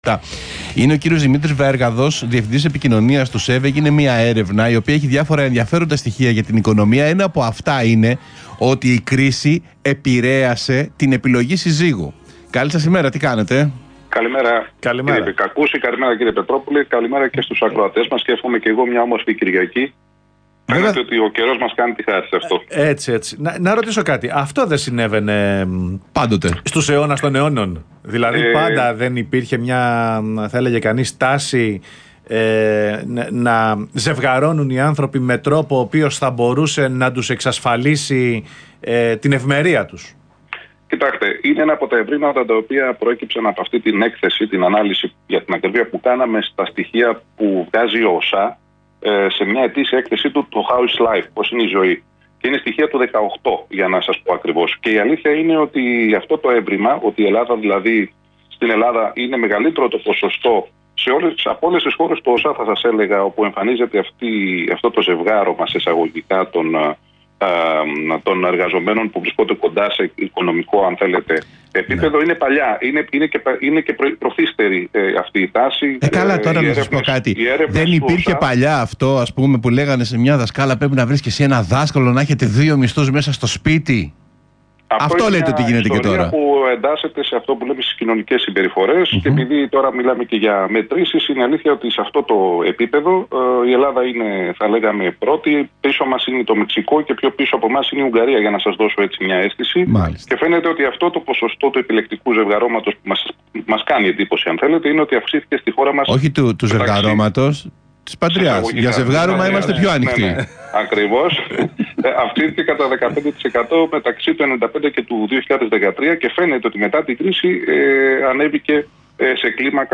Συνέντευξη
στον Ρ/Σ REAL FM